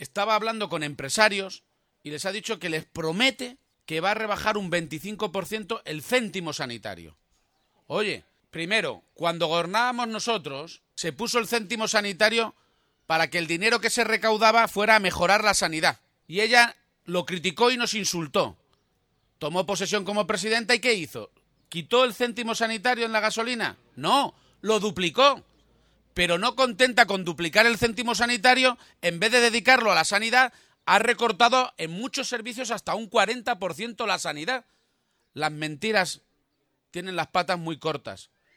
García-Page, en una comida popular en el Parque Tecnológico de la localidad de Pedro Muñoz, ha querido recordar que Cospedal criticó duramente el establecimiento del céntimo sanitario por el anterior gobierno socialista cuando el dinero se destinaba a mejorar la sanidad regional.